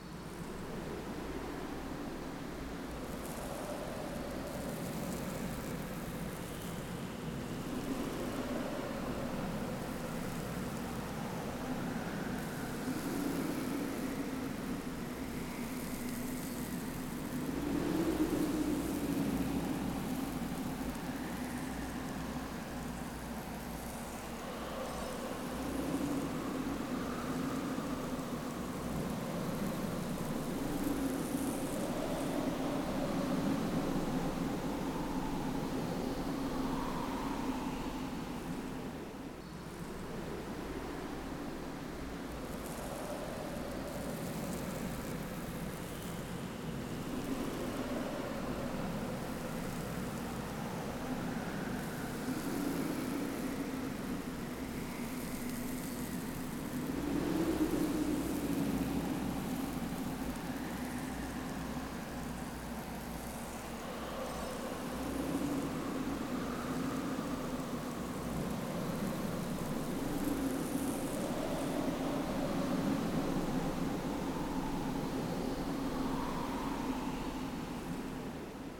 SoundEffects / Ambience